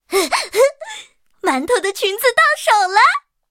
M4A3E2小飞象司令部语音2.OGG